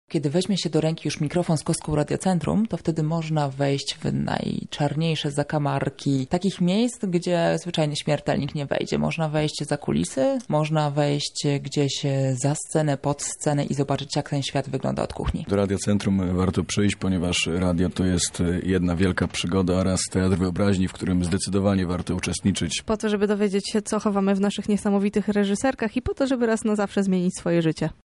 Dlaczego warto do nas dołączyć mówią sami dziennikarze Radia Centrum.